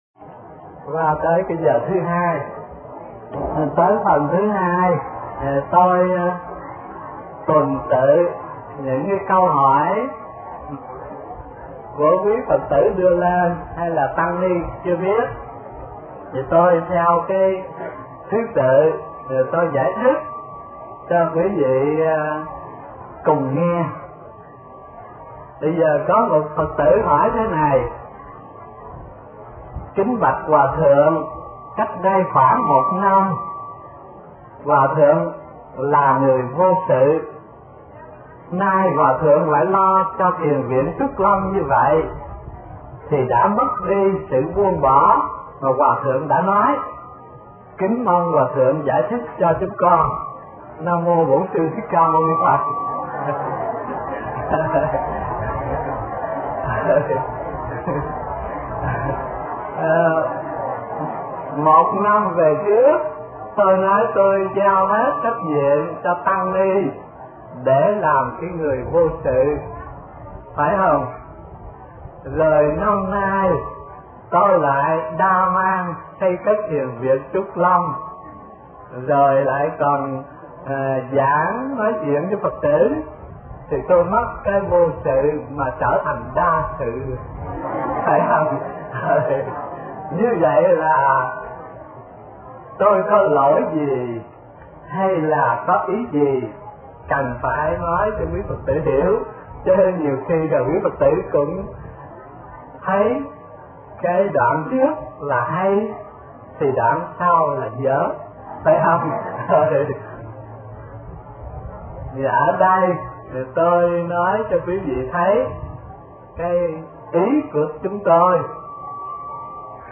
Mp3 Pháp Thoại Thắc Mắc Hòa Thượng Vô Sự Hay Hữu Sự – Tham vấn HT Thích Thanh Từ 2